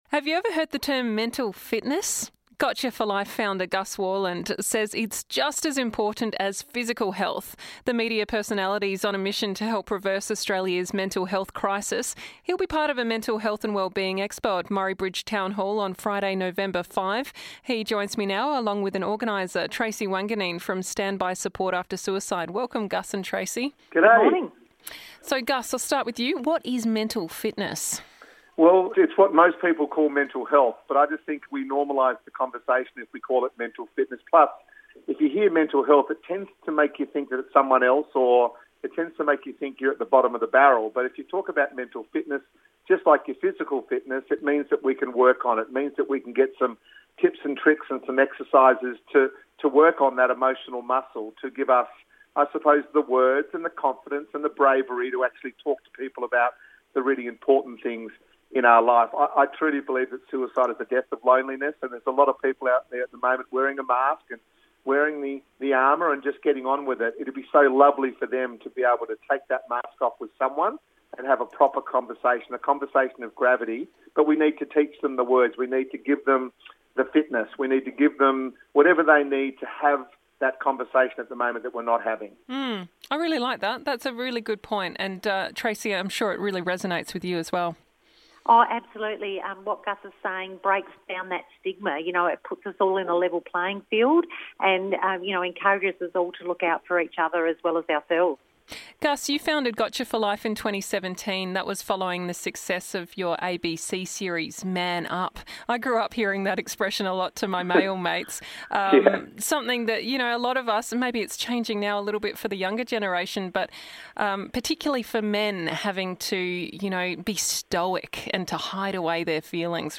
for a chat about his mission